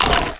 meleehit1.wav